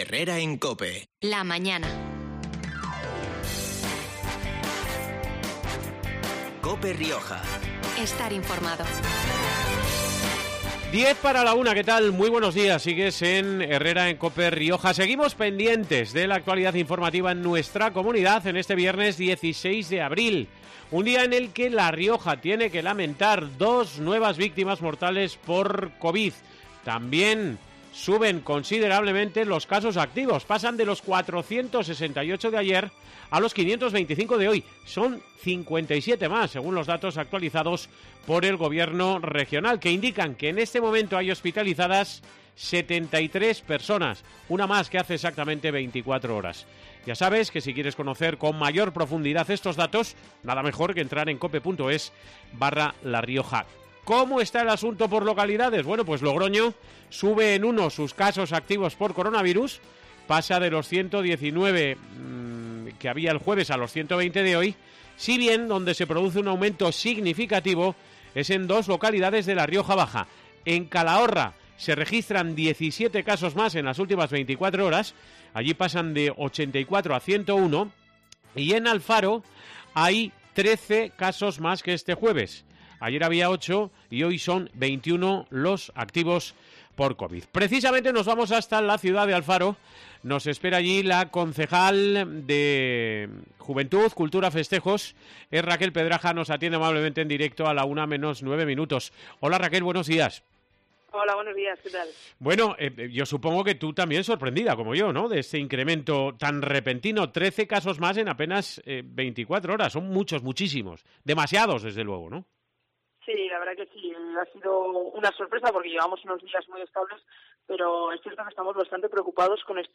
Bien sea conciertos , obras de teatro o corridas de toro Sobre este particular se ha pronunciado este mediodía en COPE Rioja la concejala alfareña de Festejos, Raquel Pedraja , quien ha confirmado la creación de un comité de expertos para empezar a perfilar la confección de los cartele.